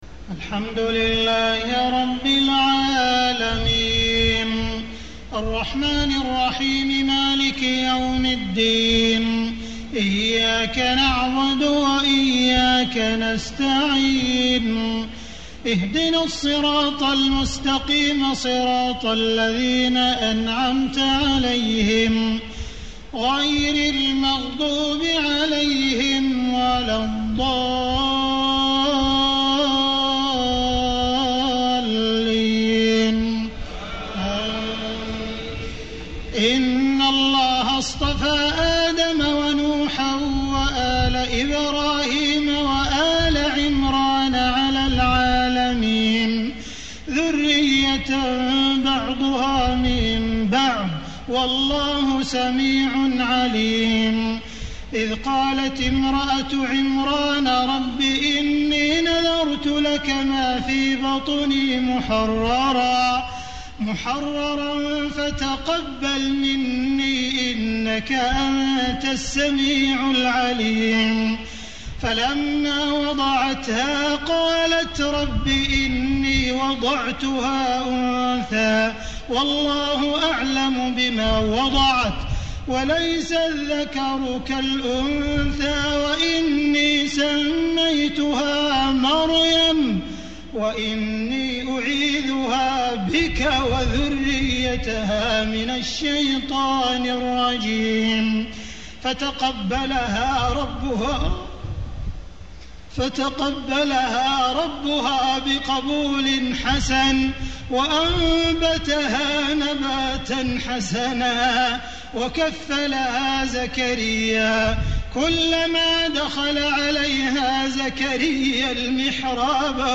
تهجد ليلة 23 رمضان 1435هـ من سورة آل عمران (33-92) Tahajjud 23 st night Ramadan 1435H from Surah Aal-i-Imraan > تراويح الحرم المكي عام 1435 🕋 > التراويح - تلاوات الحرمين